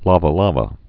(lävə-lävə)